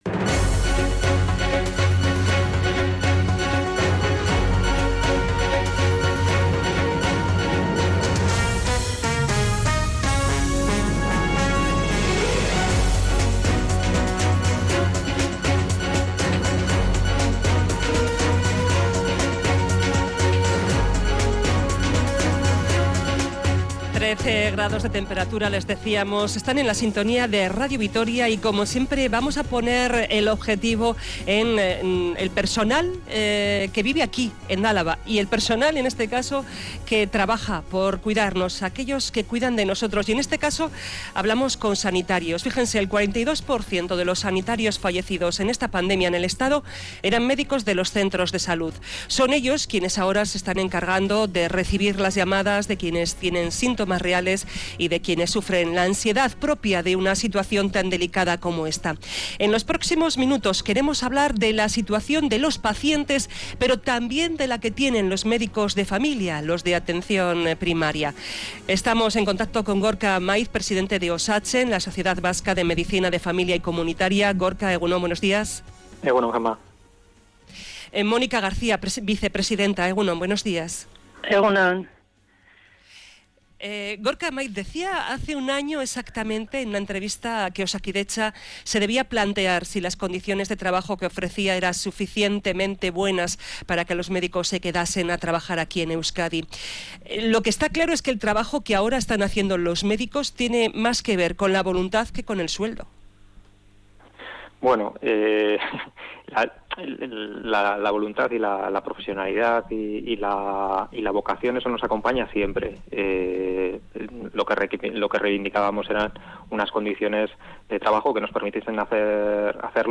Médicos de atención primaria nos cuentan su día a día en centros de salud